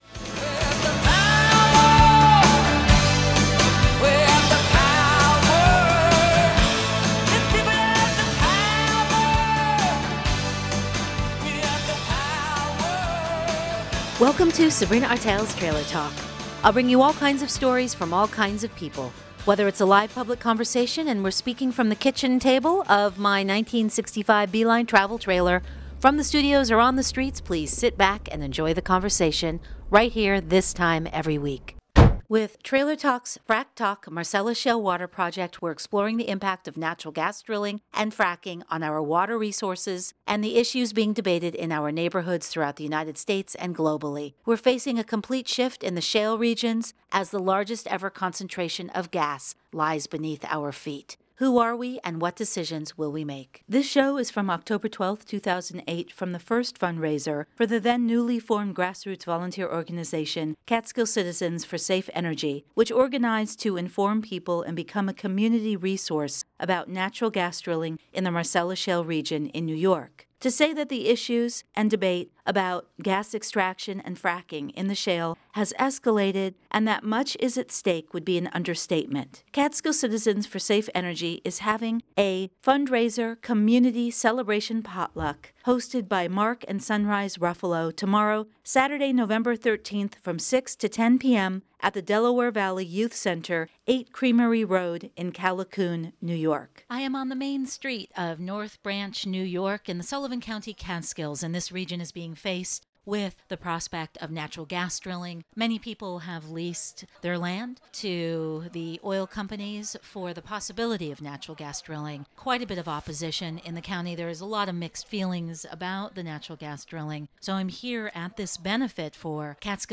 Trailer Talk went to the first benefit in North Branch, NY for the grassroots citizen group, Catskill Citizens for Safe Energy that has formed in Sullivan and Delaware Counties in the Catskills over concern about the proposed gas drilling in the Marcellus shale throughout the region.